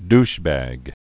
(dshbăg)